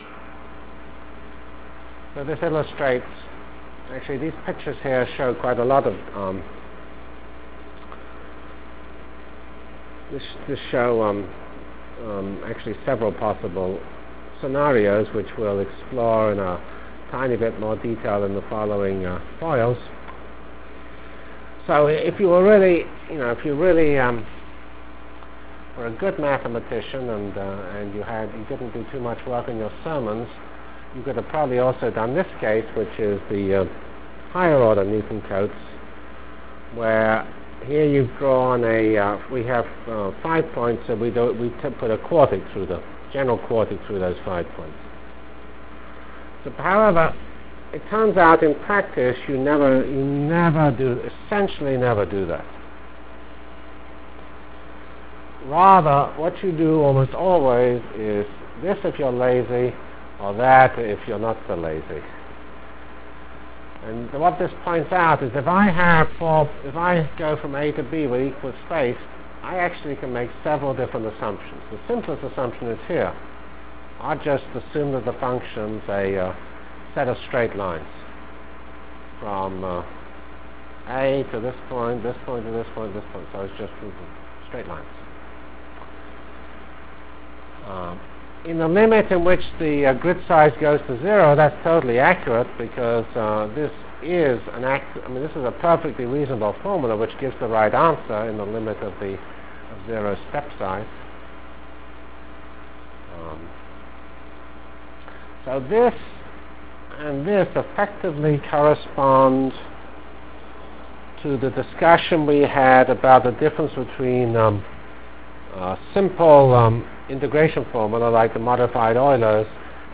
From CPS615-End of N-Body Discussion and Beginning of Numerical Integration Delivered Lectures of CPS615 Basic Simulation Track for Computational Science -- 15 October 96. *